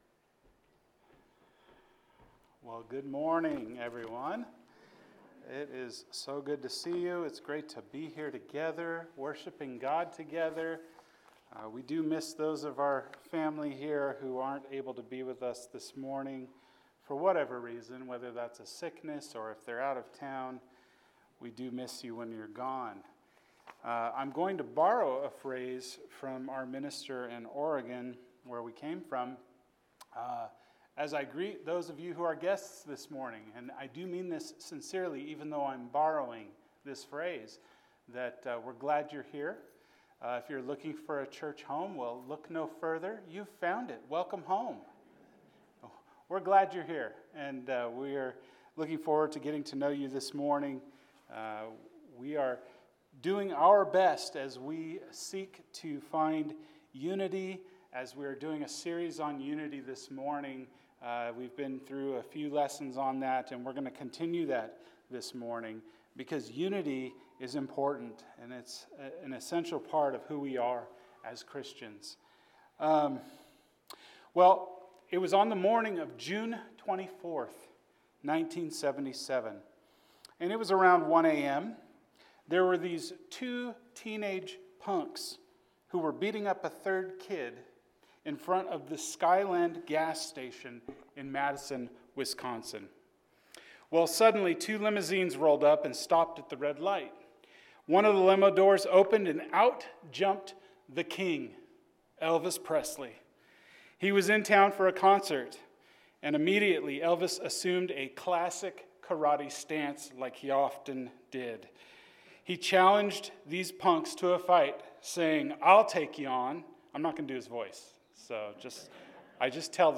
Who Do You Follow? – 1 Cor. 1:10-17 – Sermon